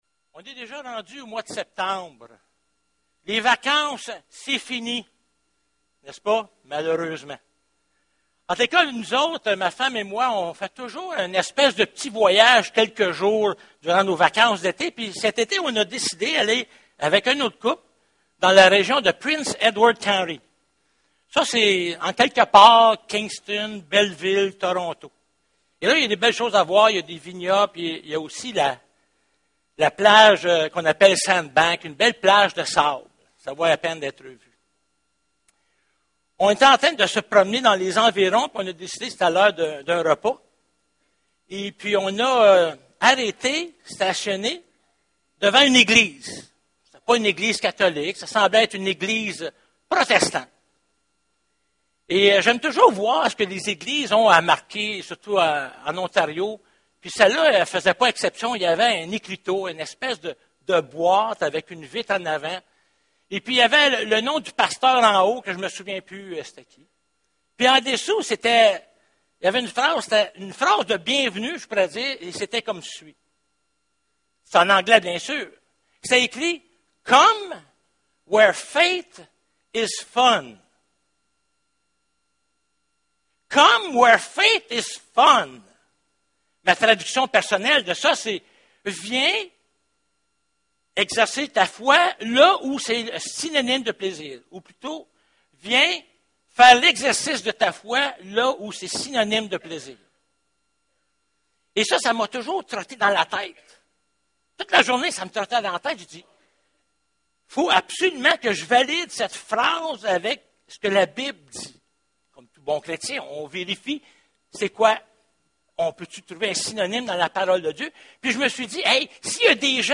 Jonas 1:1-6 Service Type: Célébration dimanche matin Comme tout athlète